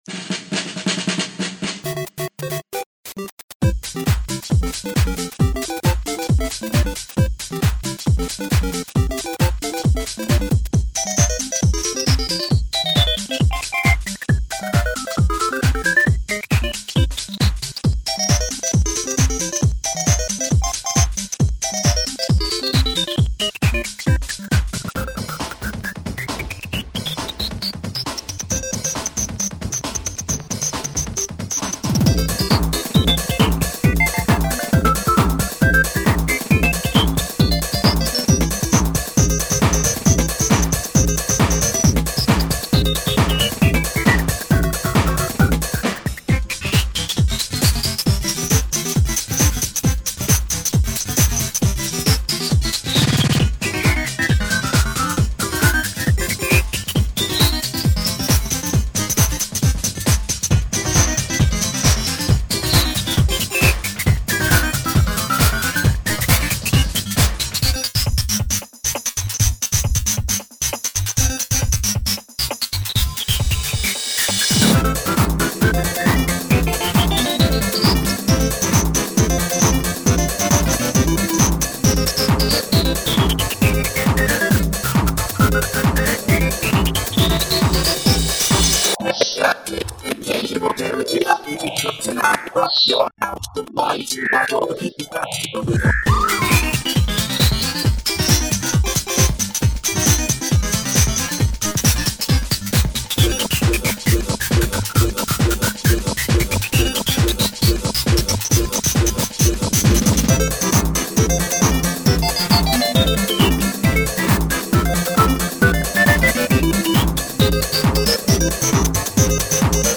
techno trance club